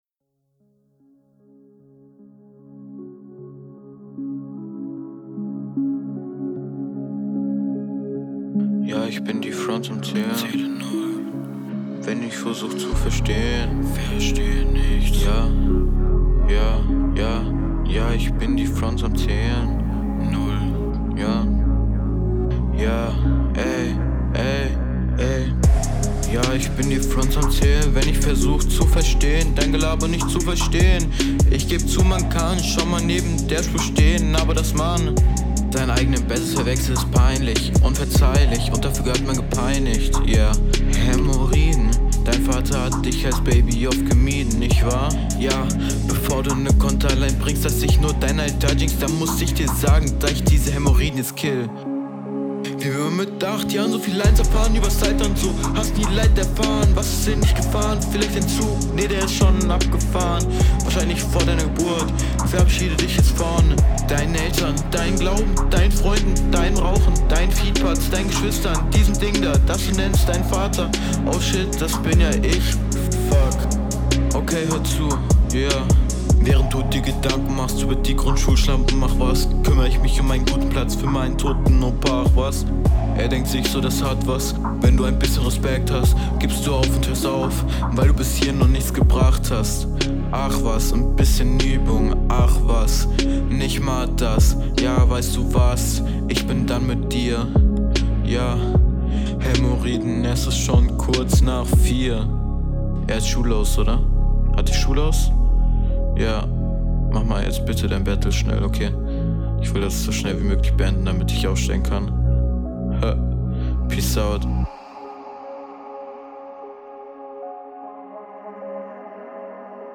ich finde die Runde sehr athmosphärisch und Lyrisch relativ solide.
Du kommst zwar nicht sehr routiniert auf dem Beat, bist jedoch meistens auf dem Takt.